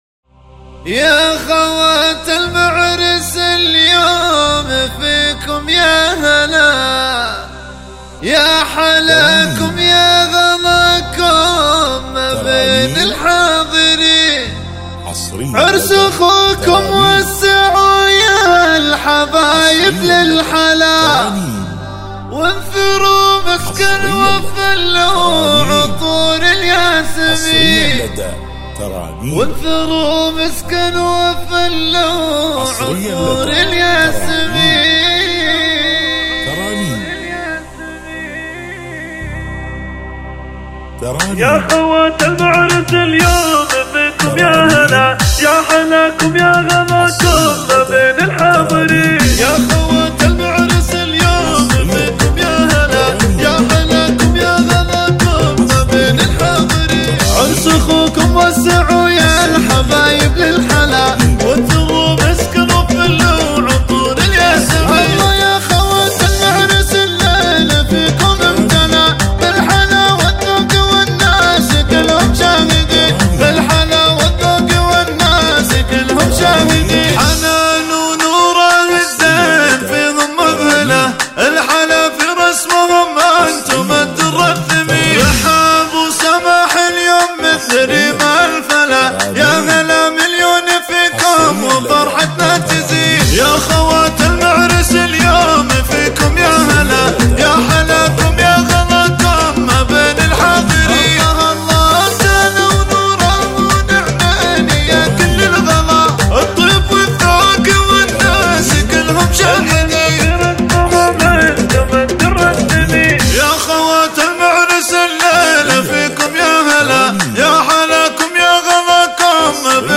زفات 2025